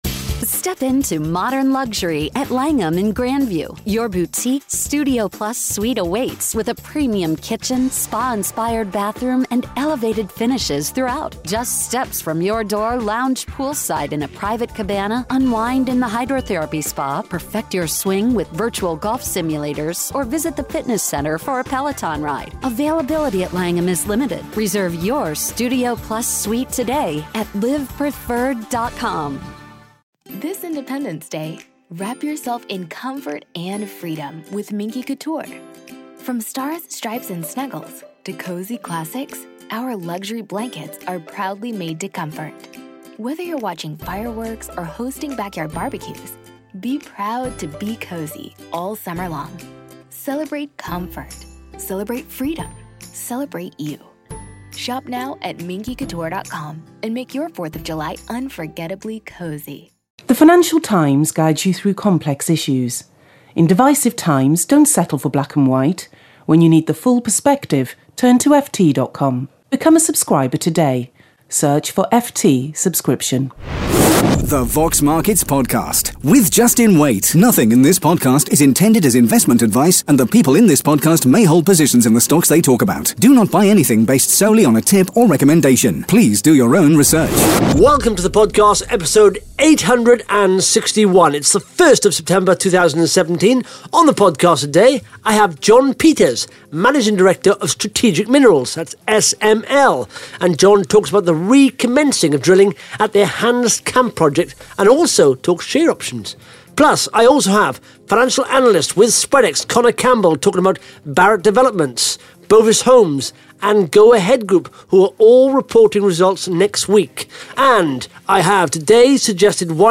(Interview starts at 1 minute 16 seconds)